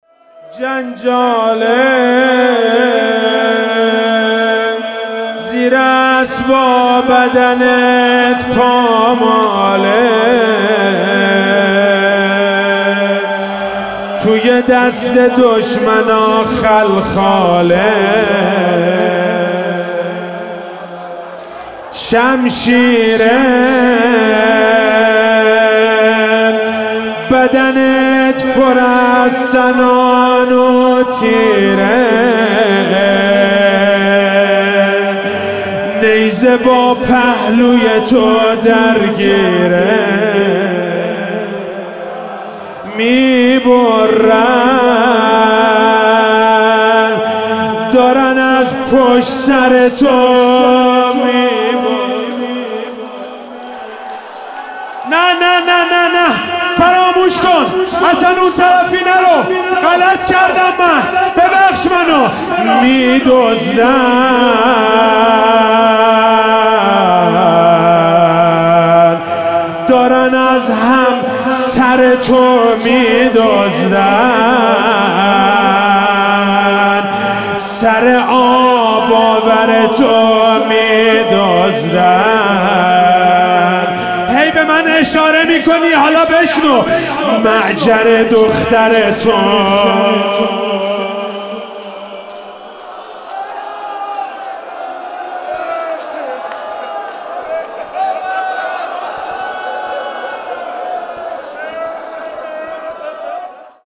جنجاله زیر اسب ها بدنت روضه فاطمیه 99(روایت75روز